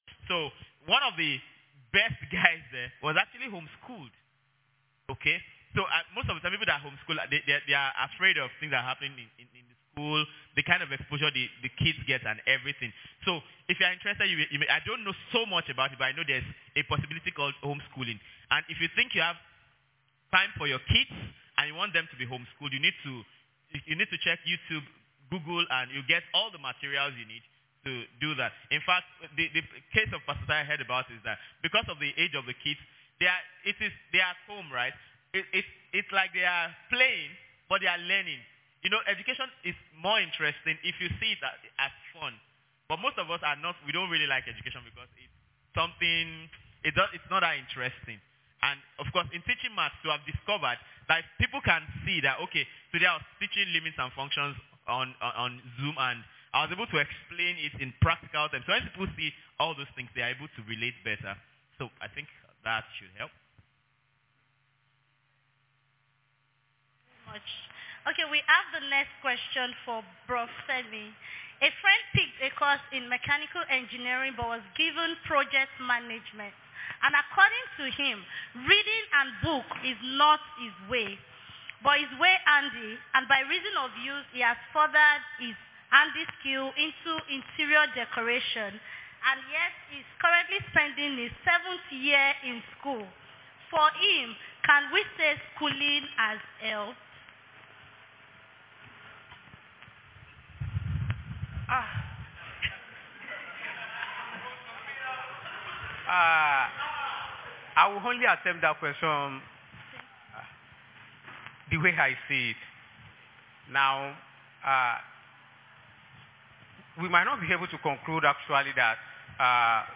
Is-School-A-Scam-Discussion.mp3